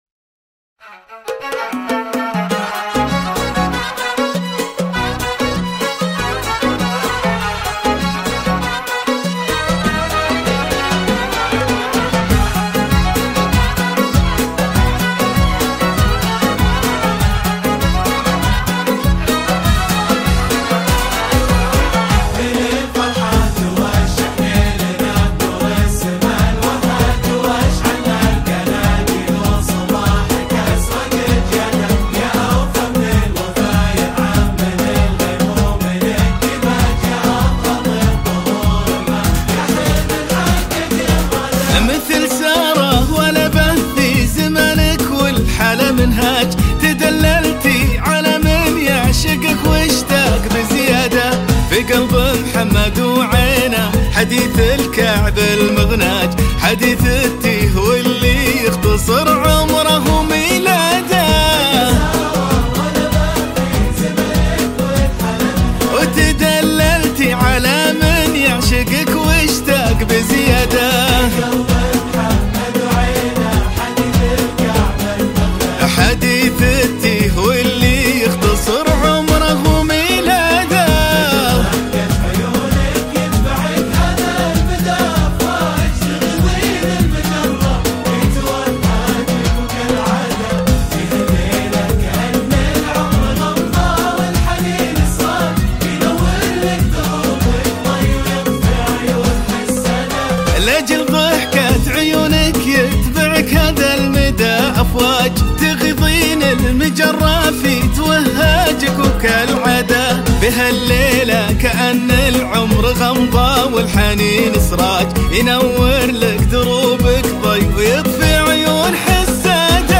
زفة المعرس